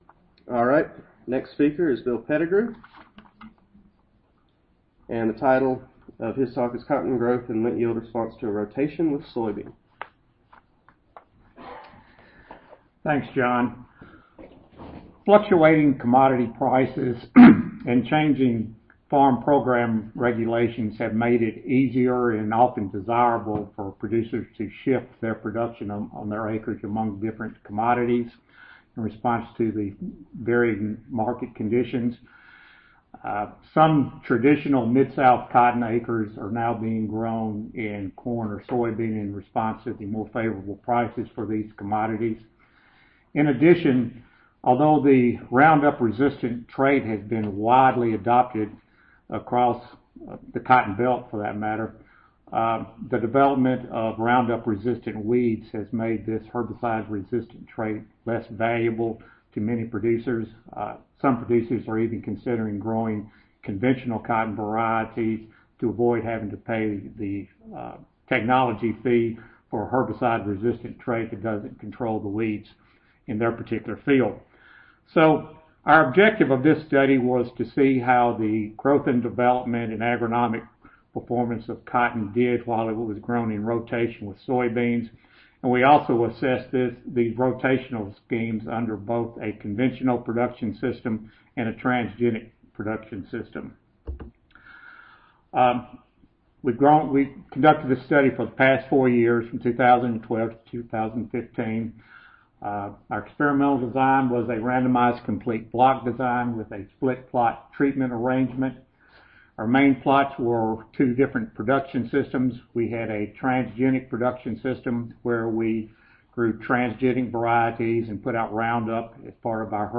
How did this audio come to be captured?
Galerie 5 (New Orleans Marriott)